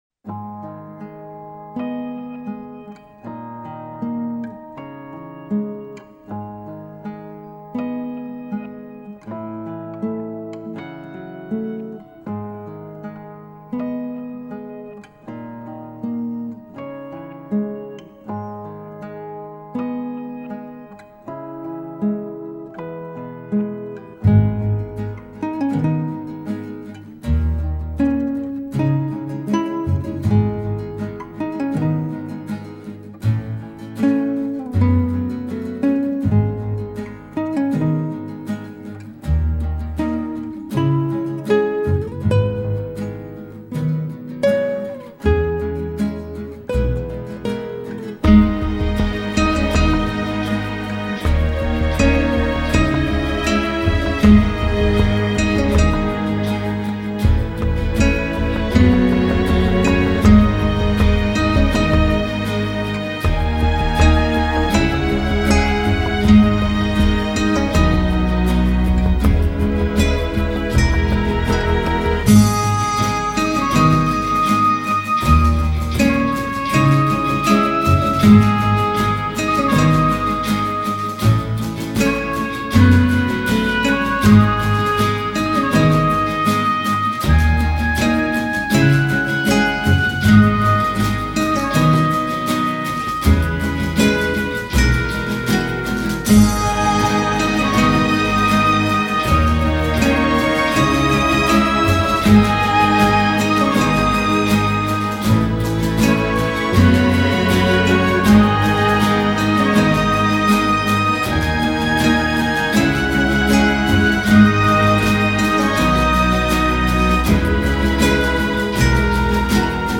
آهنگ وسترنی